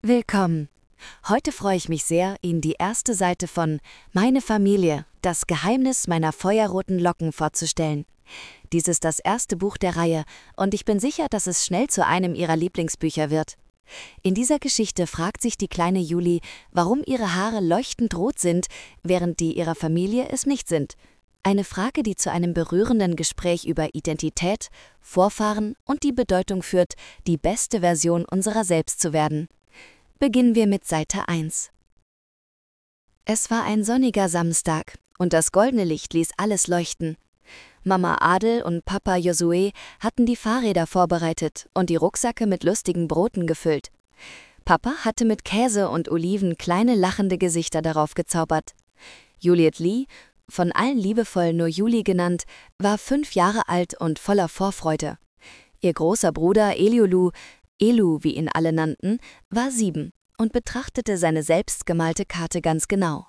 Lesungen (Audio)